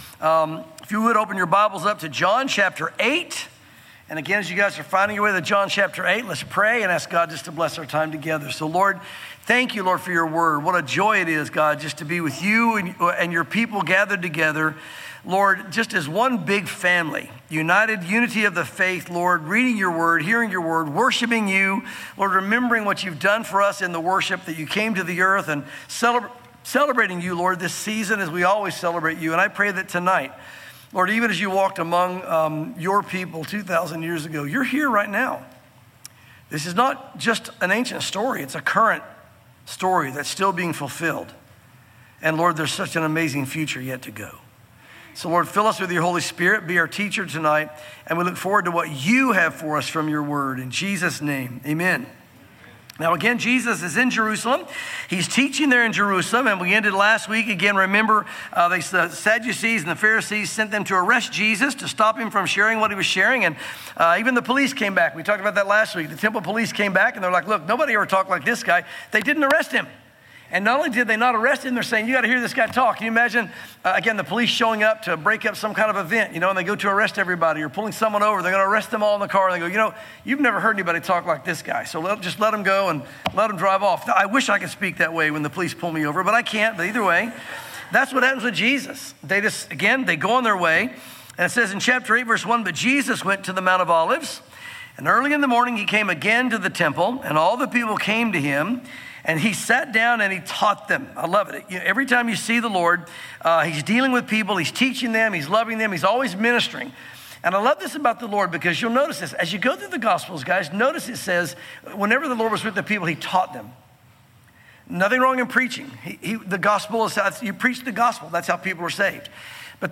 sermons John Chapters 8 & 9